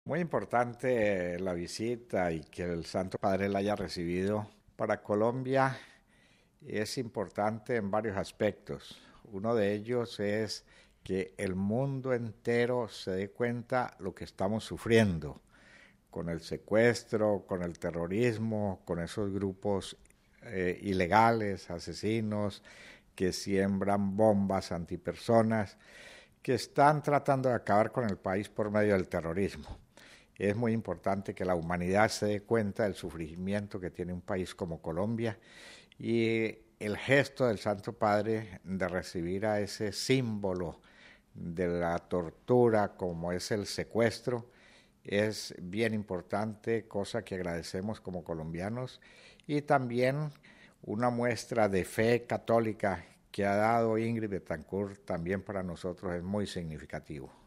Martes, 2 sep (RV).- El embajador de Colombia ante la Santa Sede, Juan Gómez Martínez, nos ha concedido unas palabras destacando la importancia para el pueblo colombiano de esta audiencia del Papa y del testimonio de fe manifestado por la señora Betancourt: RealAudio